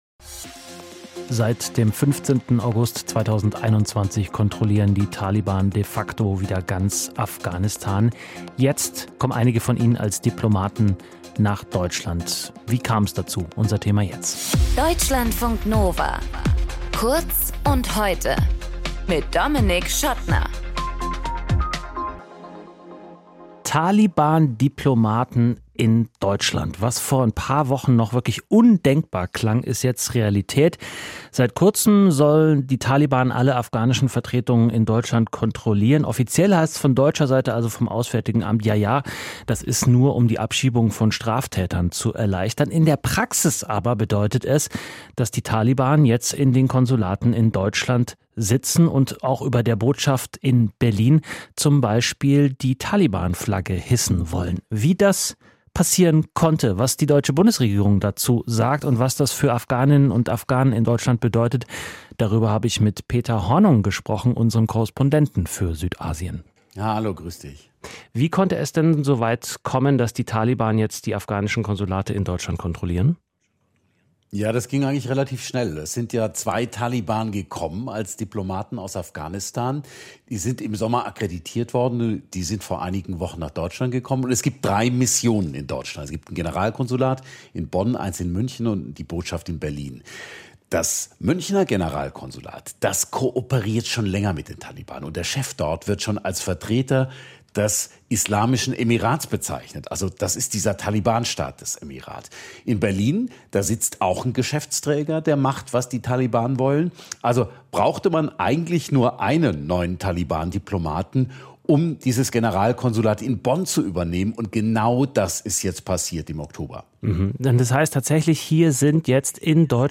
Moderator
Gesprächspartner